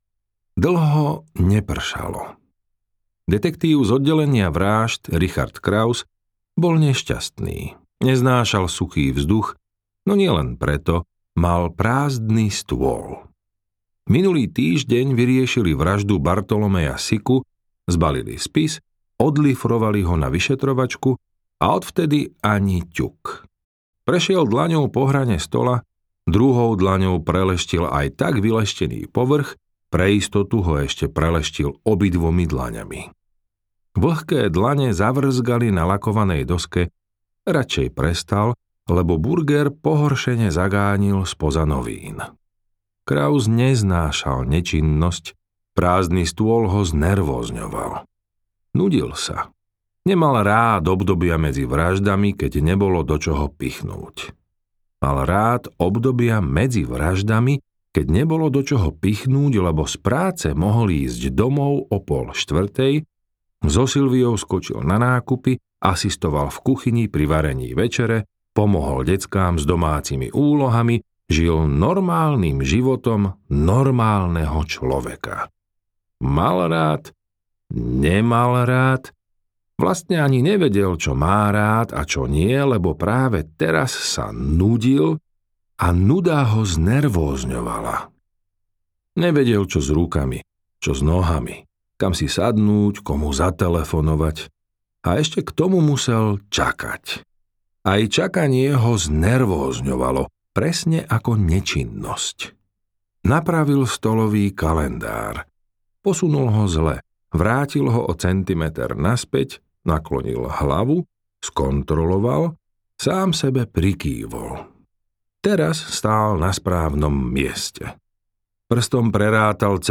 Dáma kontra strelec audiokniha
Ukázka z knihy
dama-kontra-strelec-audiokniha